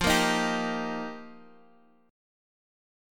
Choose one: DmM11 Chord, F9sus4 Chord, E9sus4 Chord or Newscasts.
F9sus4 Chord